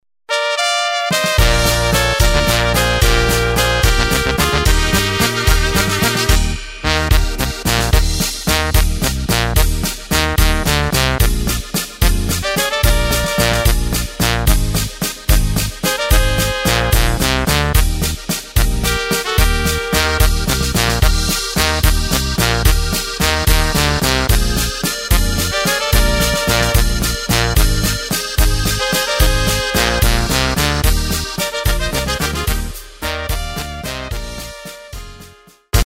Takt: 3/4 Tempo: 220.00 Tonart: Eb
2:53 min Walzer Volksmusik!